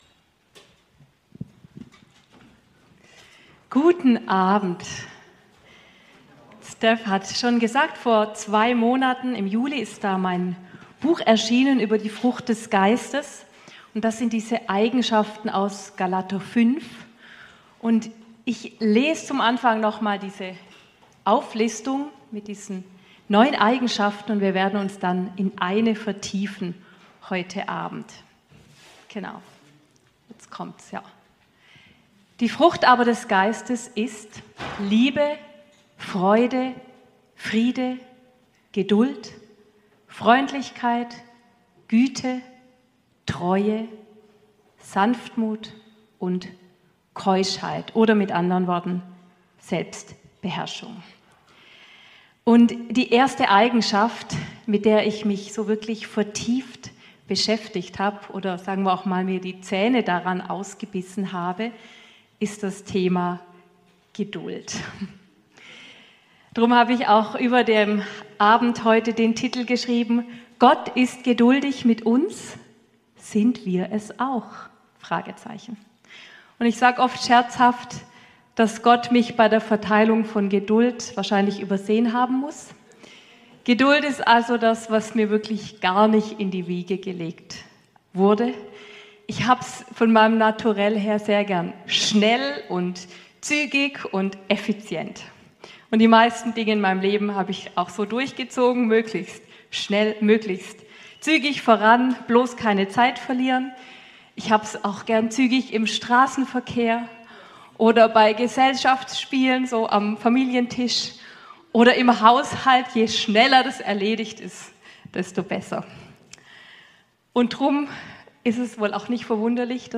Dienstagsgottesdienst vom 2. September 2025